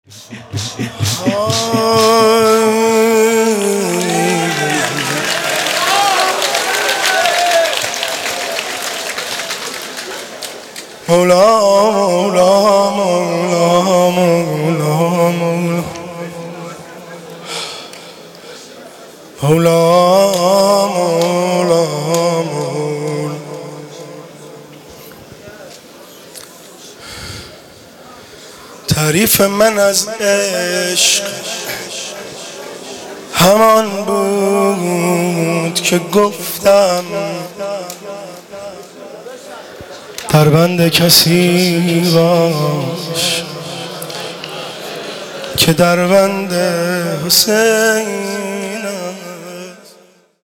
مناسبت : شهادت حضرت فاطمه زهرا سلام‌الله‌علیها
قالب : شعر خوانی